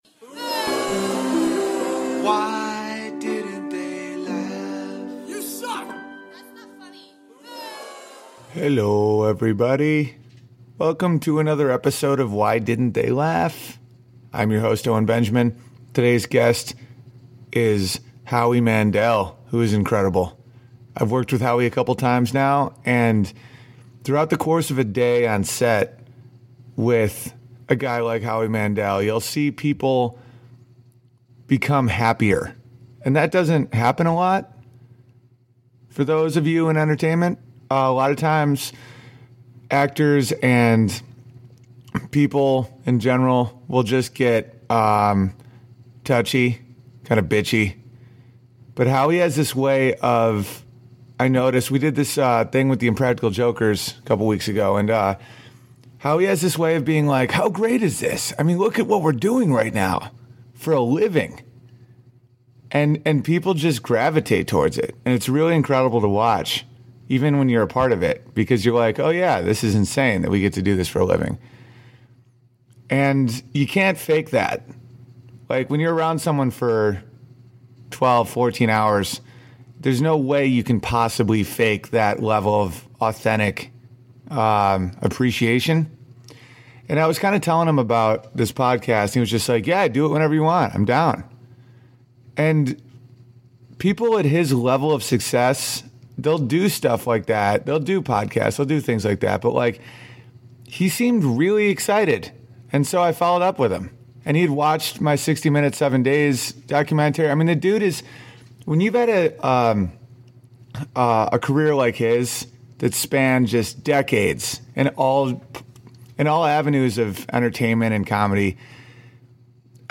Awesome talk with Howie Mandel.